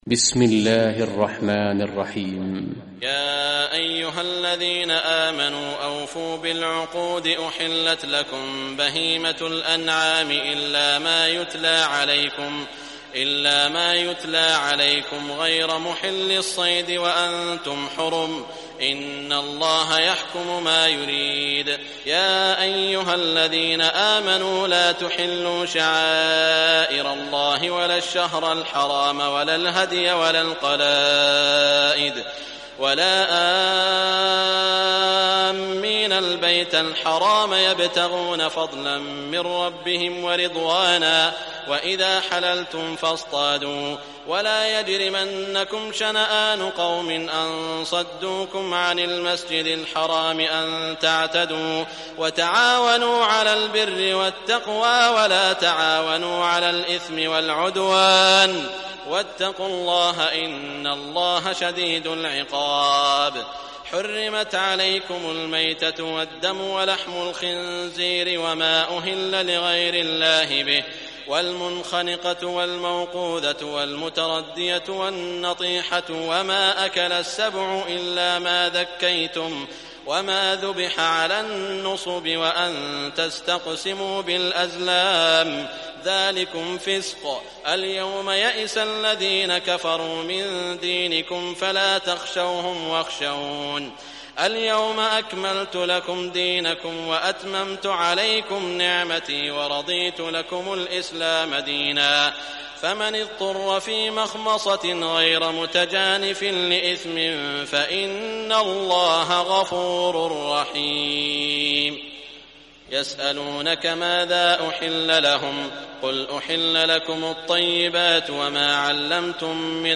Surah Al-Maidah Recitation by Sheikh Saud Shuraim
Surah Al-Maidah, listen or play online mp3 tilawat / recitation in Arabic in the beautiful voice of Sheikh Saud Al Shuraim.